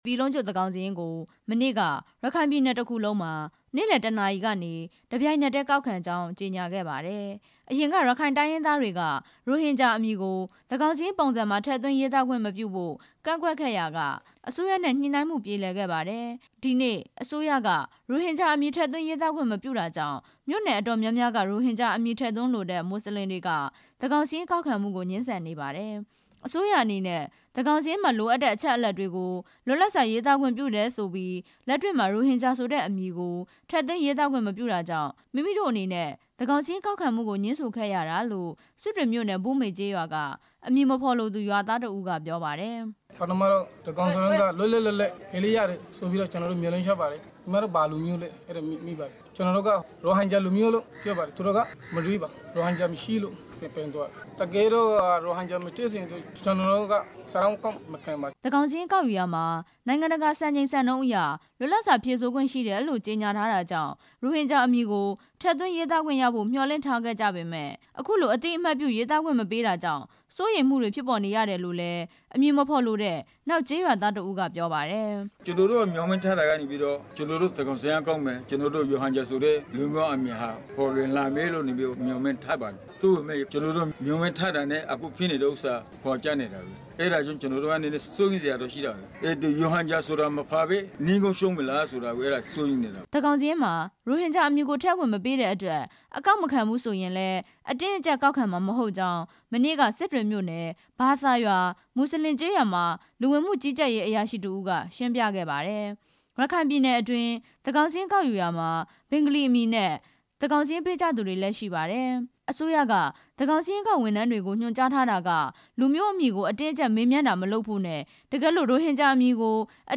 ရခိုင်ပြည်နယ် သန်းခေါင်စာရင်းကောက်ယူမှု တင်ပြချက်